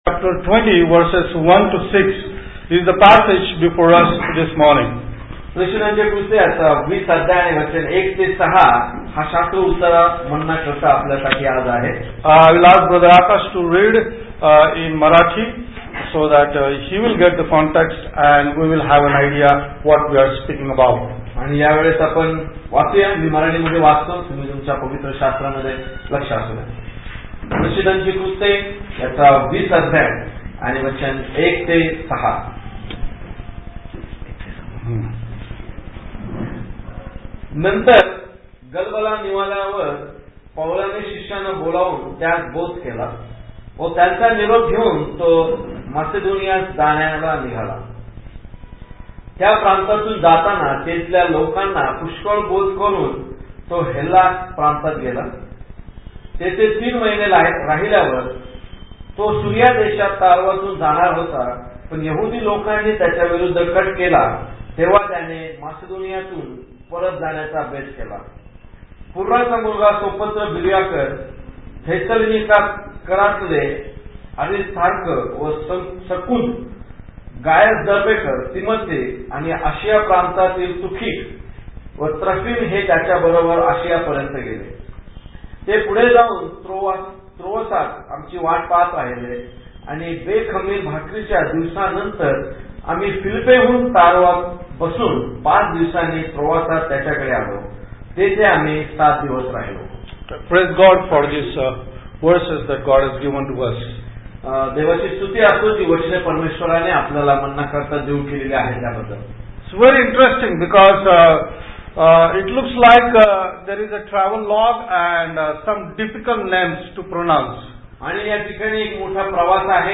Bible Text: Acts 20:1-6 | Preacher: